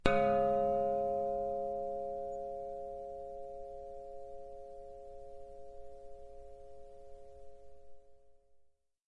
描述：在楼梯间撞击栏杆的钟声
Tag: 钟号锣 打击乐器